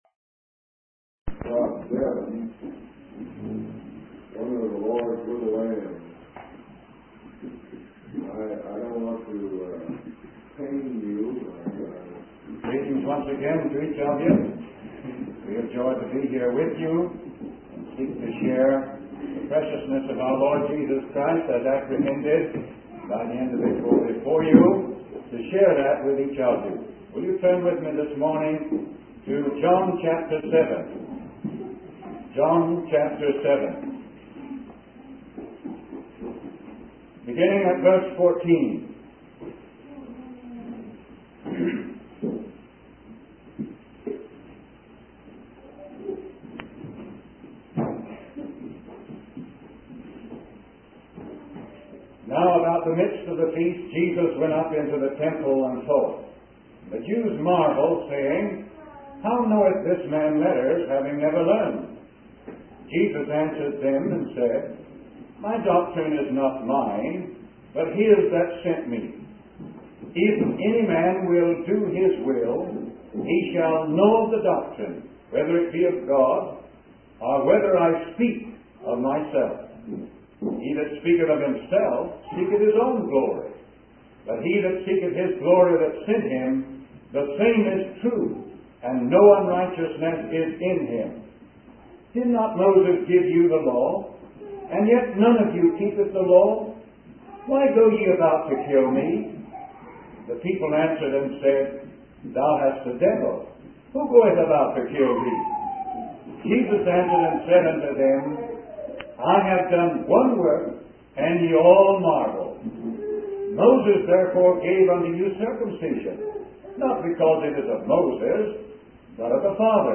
In this sermon, the preacher reflects on a woman who drove her car to a river bridge and ended up in the hospital, hinting at her longing for something she never found. The preacher emphasizes the importance of coming to Jesus for rest and salvation, using the verse from Matthew 11:28-30. The preacher also references the story of Jesus turning water into wine in John chapter 2, highlighting the significance of Jesus' power to transform and bring joy.